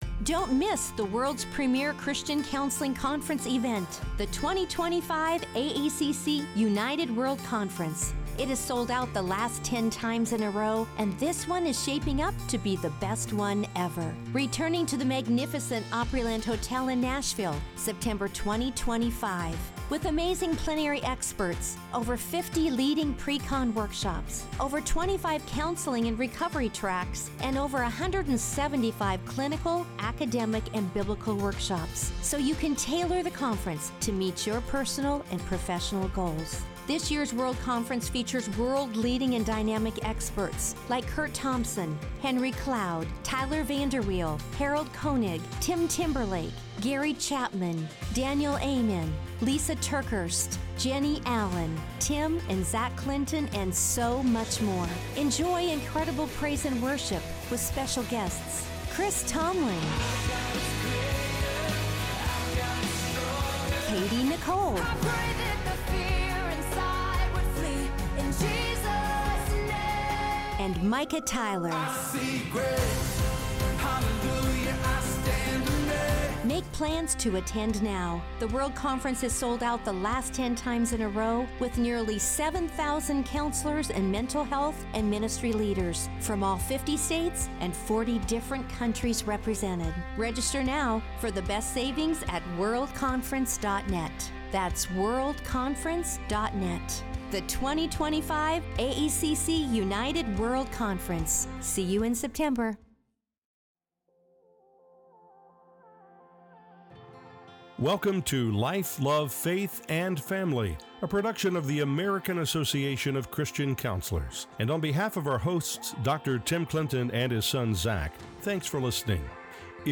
for an inspiring conversation about her testimony.